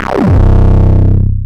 Filta Bass (JW3).wav